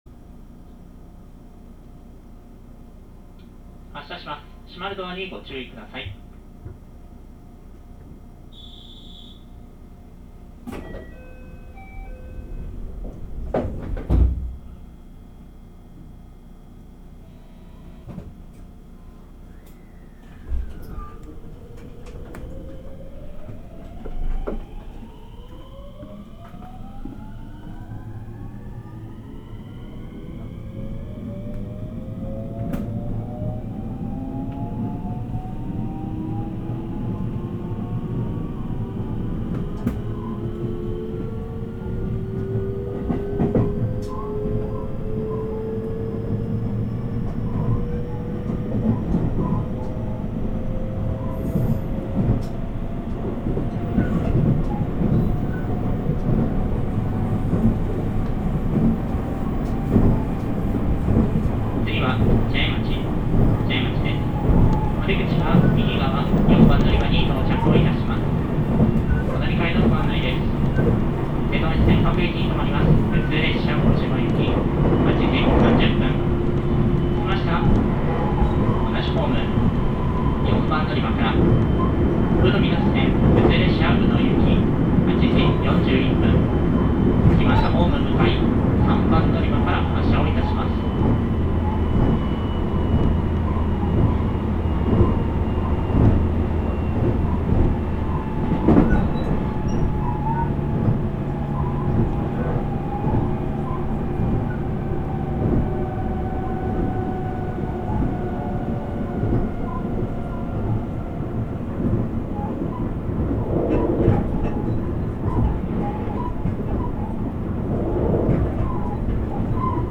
走行音
録音区間：早島～茶屋町(マリンライナー9号)(お持ち帰り)